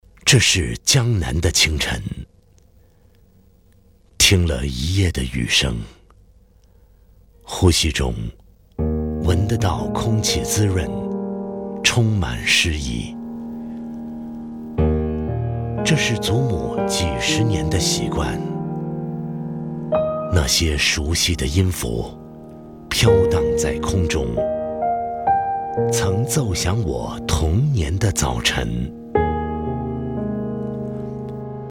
男声配音
标签： 浑厚
配音风格： 轻快 浑厚 科技 欢快